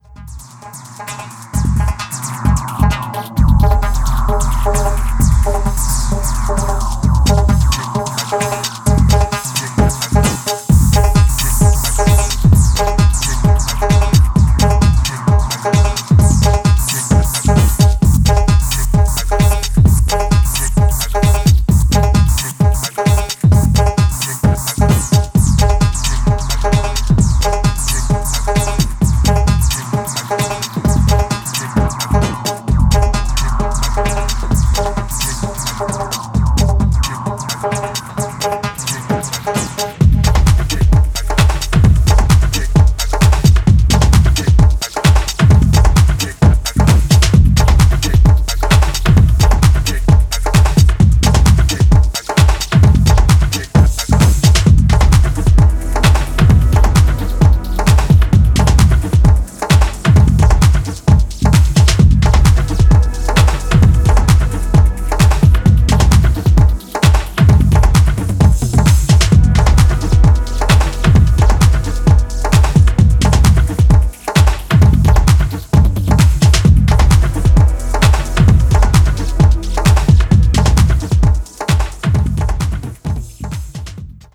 アフロ・オリエンテッドな要素とボトムヘヴィなリズムを組み合わせたダークでエネルギッシュなトラック群を展開しており
ツイステッドなグルーヴが蛇行するトライバル・トラック